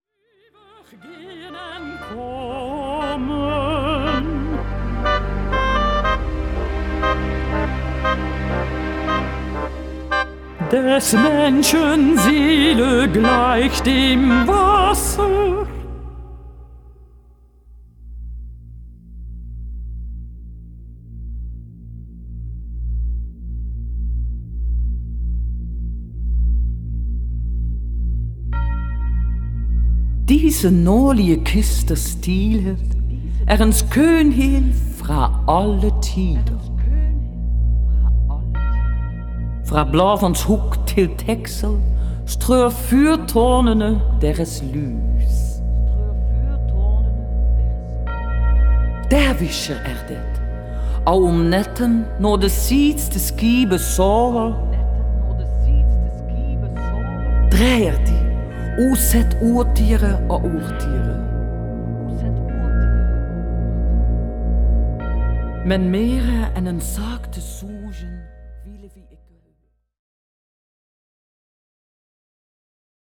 electronic-based compositions